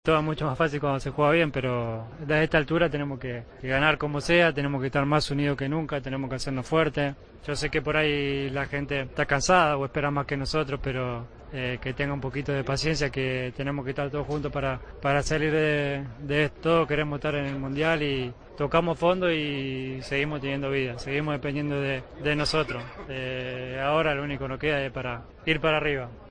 El capitán de la albiceleste reconoció ante los medios la dolorosa derrota sufrida en Brasil y que ahora a Argentina solo le queda "seguir unida" para "estar en el Mundial".